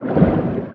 Sfx_creature_pinnacarid_swim_fast_06.ogg